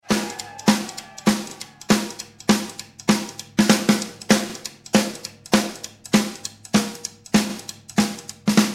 GROOVE 1 GROOVE 2